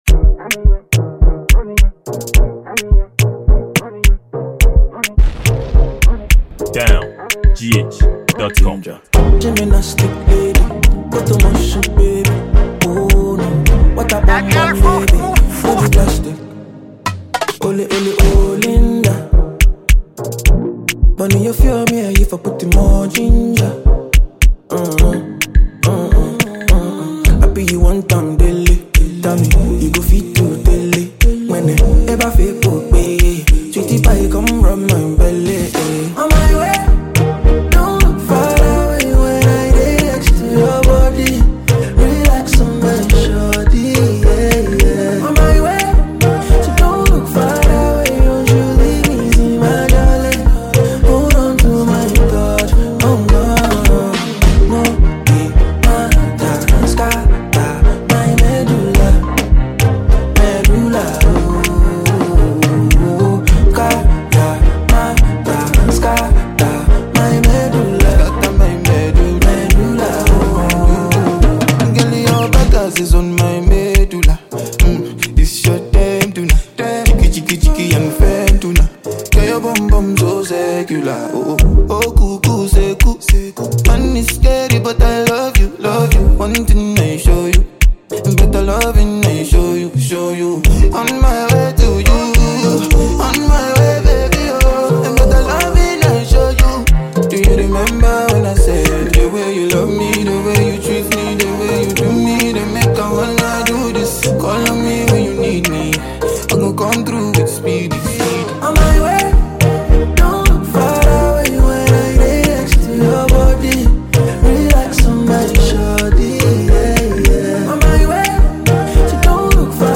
Ghanaian afrobeat highlife singer and songwriter
Ghana afrobeat song.